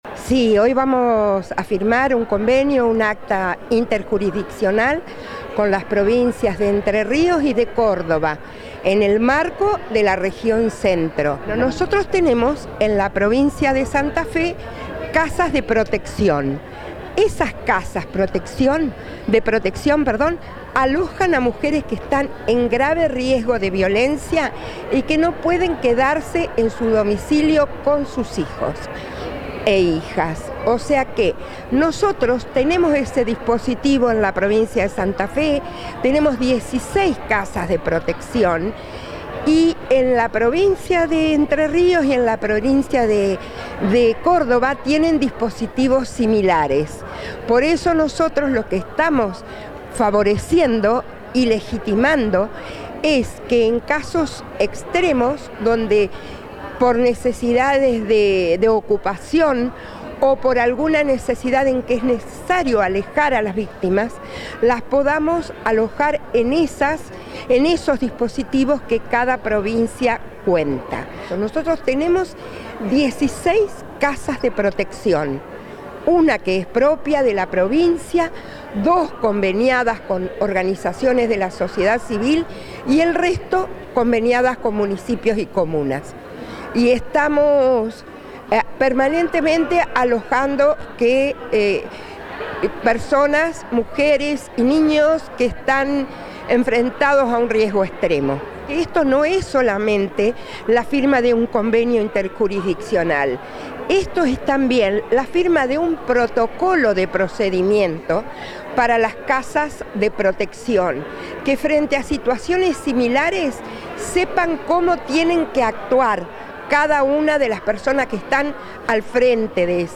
Declaraciones de Tate y Giaccone